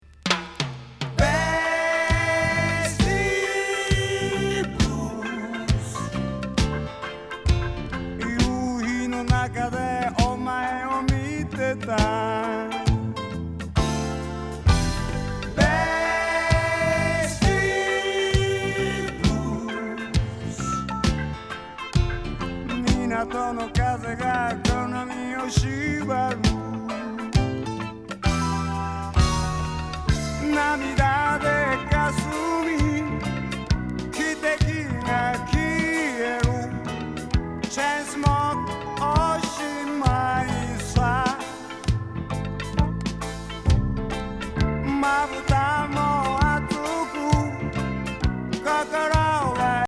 ナイス・ヤサグレ・レゲー